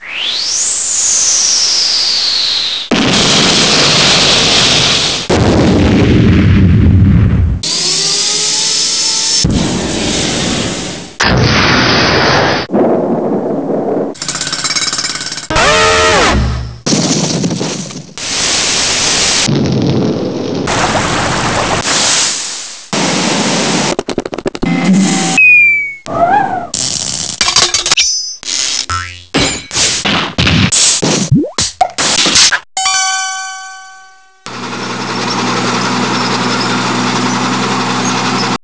: contains sound effects used during the game.